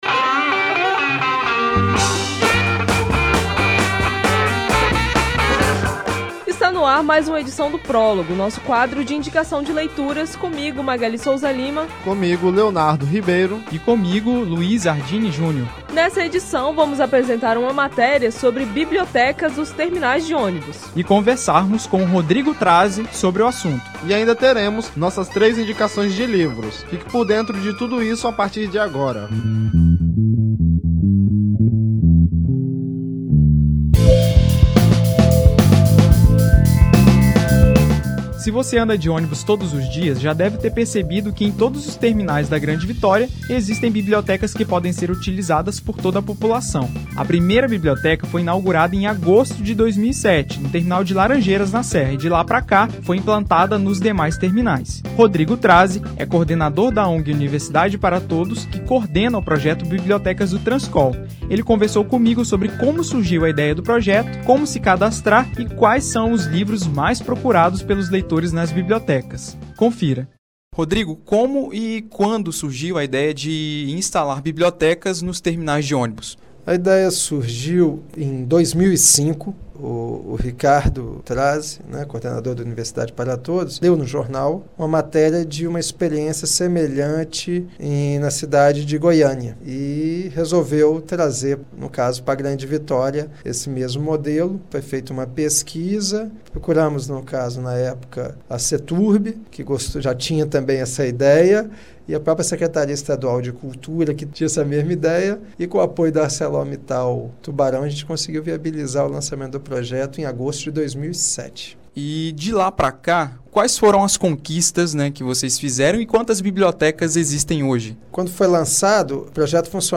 O Prólogo desta semana fez uma entrevista